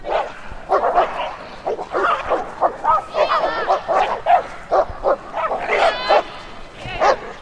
狗 " 狗 吠叫
描述：狂吠的狗。